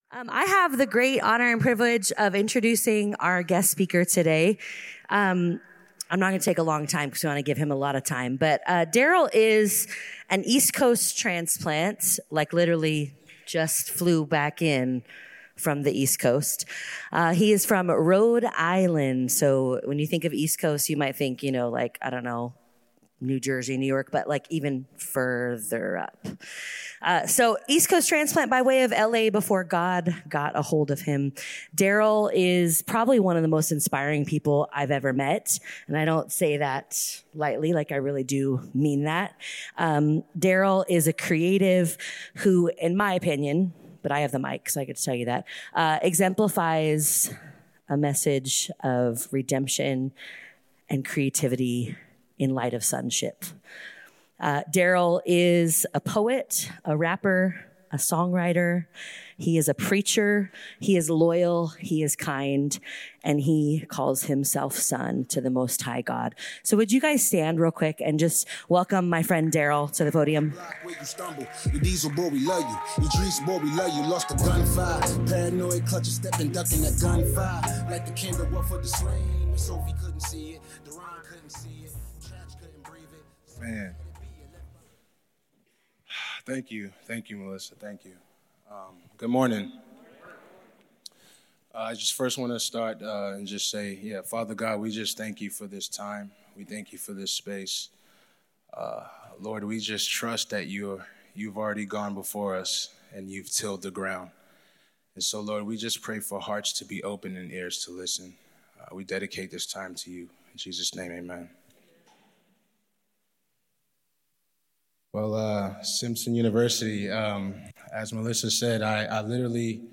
This talk was given in chapel on Wednesday, February 19th, 2025 God Bless you.